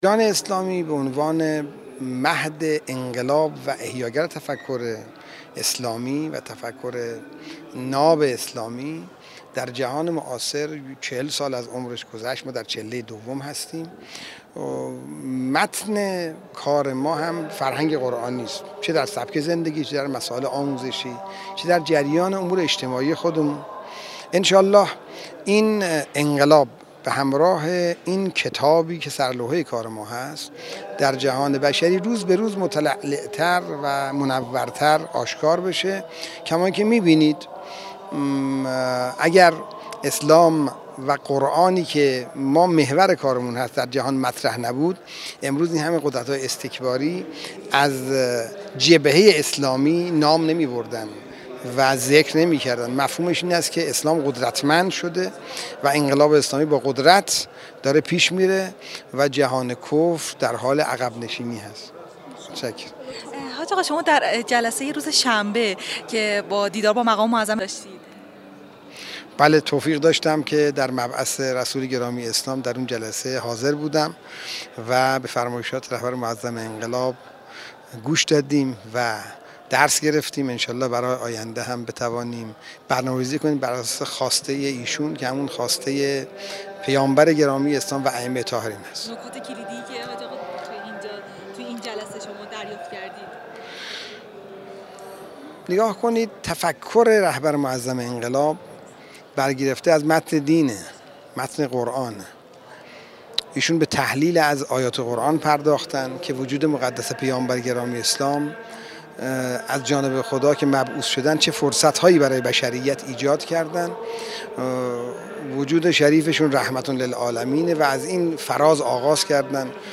سیدمهدی خاموشیحجت‌الاسلام والمسلمین سیدمهدی خاموشی، رئیس سازمان اوقاف و امور خیریه در حاشیه سی‌و‌نهمین دوره مسابقات بین‌المللی قرآن‌ جمهوری‌ اسلامی‌ ایران در گفت‌وگو با ایکنا بیان کرد: چهل سال از عمر ایران اسلامی به عنوان مهد انقلاب و احیاگر تفکر ناب اسلامی در جهان معاصر گذشت و ما در چله دوم هستیم.